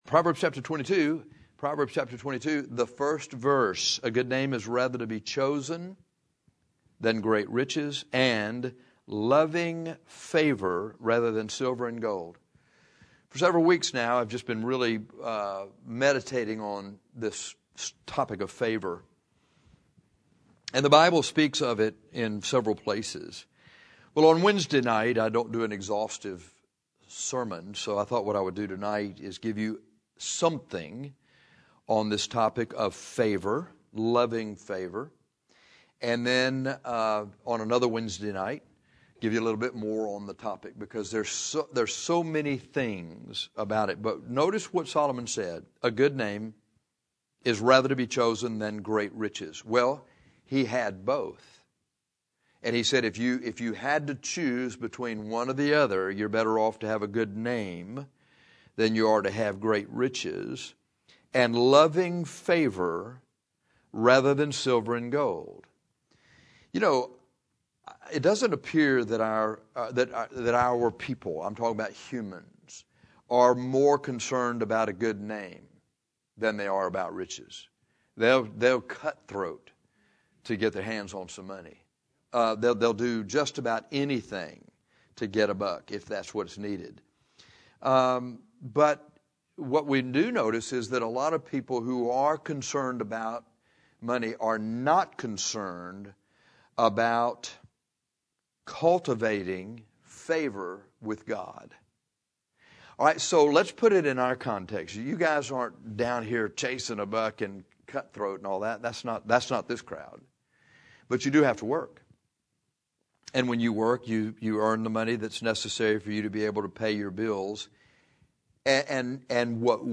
One way to find favor with God is through righteousness. This is why we call this short Wednesday night message, "Favor by Righteousness".